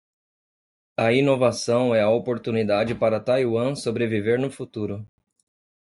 Pronounced as (IPA) /tajˈwɐ̃/